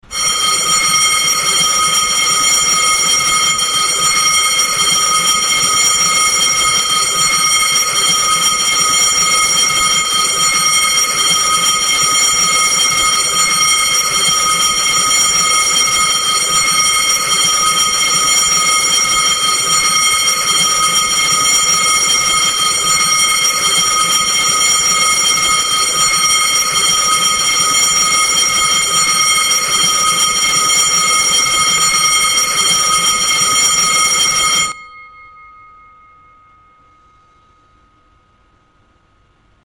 skolsko zvono.mp3